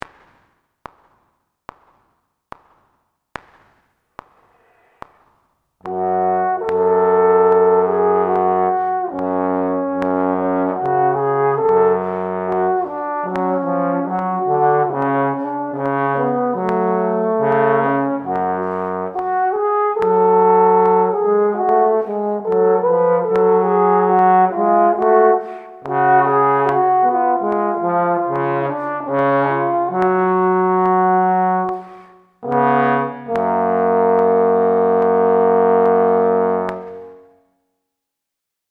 Here’s the audio of the (individual) harmony parts.
Min-06-f-sharp.mp3